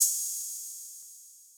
Open Hats
DRZAOpenHat3.wav